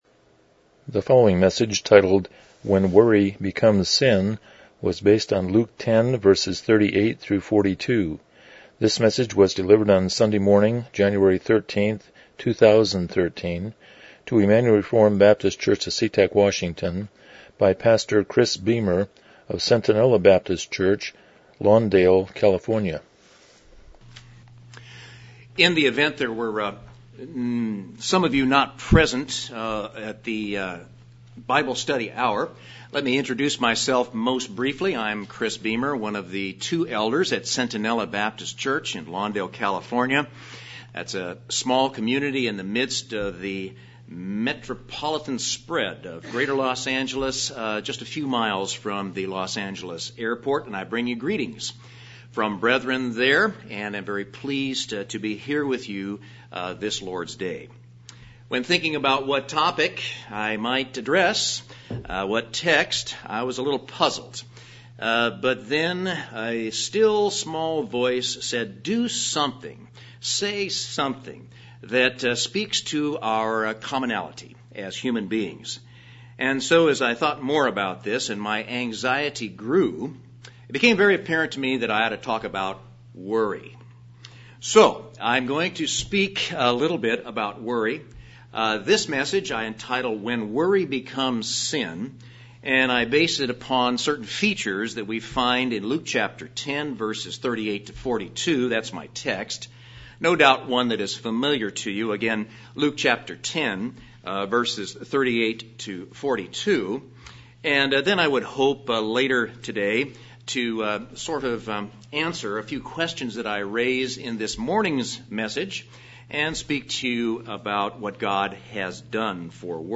Luke 10:38-42 Service Type: Morning Worship « Confidence in God’s Care